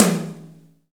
TOM GRINDE07.wav